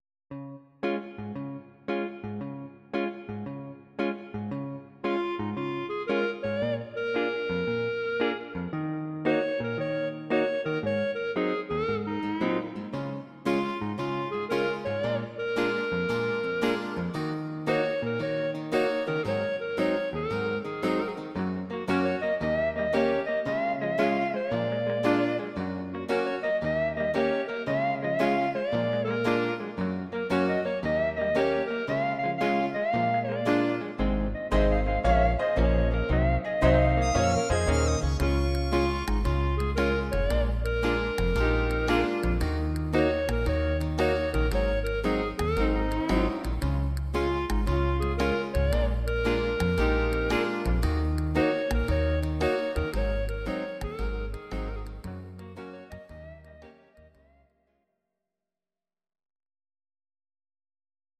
Audio Recordings based on Midi-files
Our Suggestions, Pop, Rock, 1960s